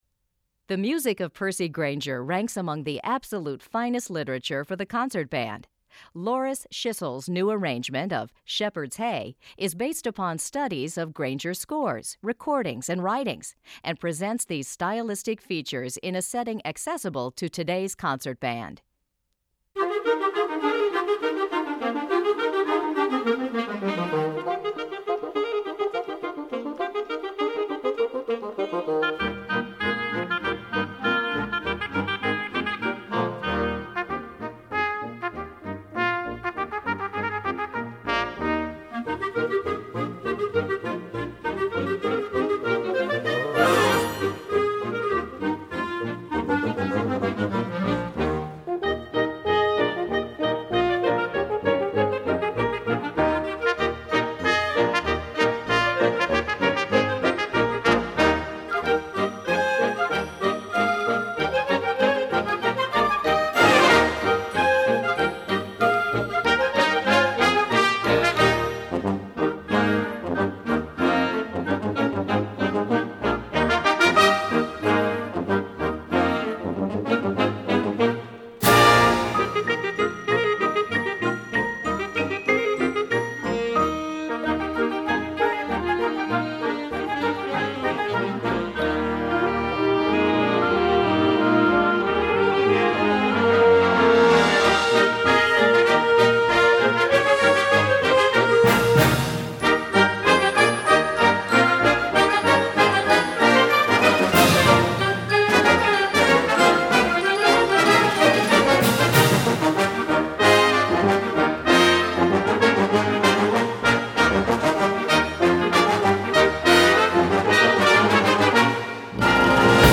Besetzung: Blasorchester
for modern concert bands